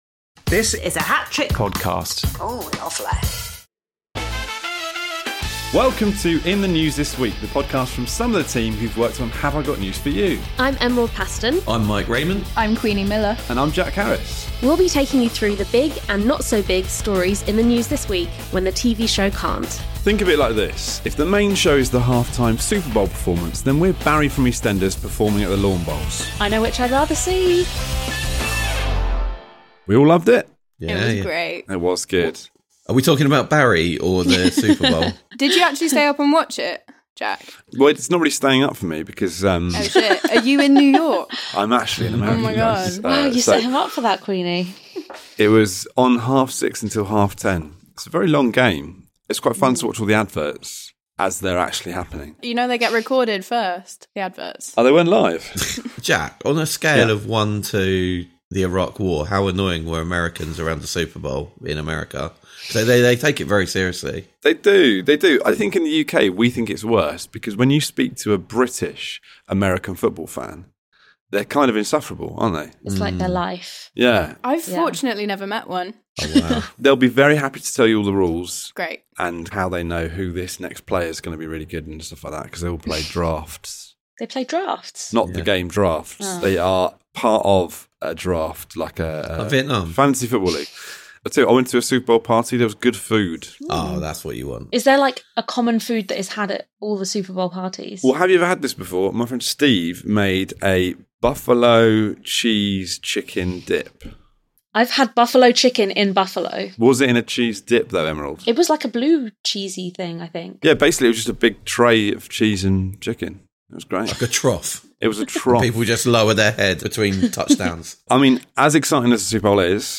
The team discuss how Labour and the Tories are responding to Reform’s recent rise in the polls, sacked Labour ministers leaked WhatsApps chats and Angela Rayner’s alleged comments about Keir Starmer and Prince Andrew.